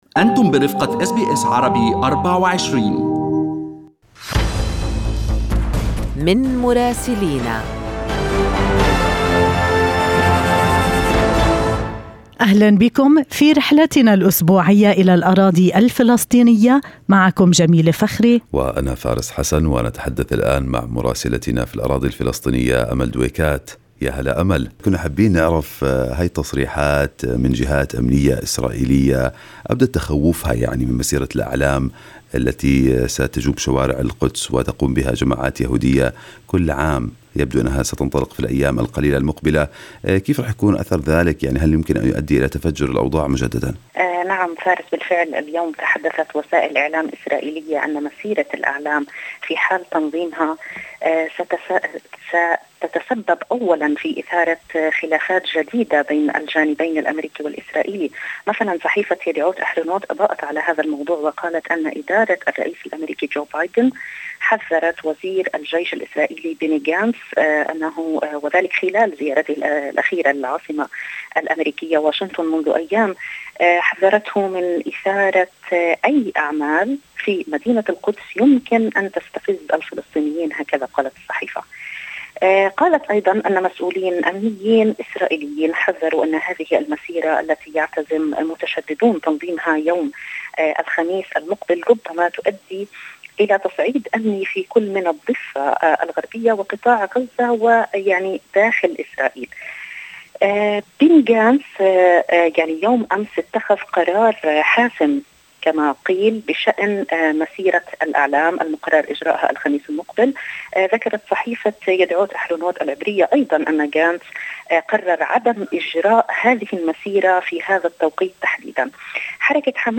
يمكنكم الاستماع إلى تقرير مراسلتنا في رام الله بالضغط على التسجيل الصوتي أعلاه.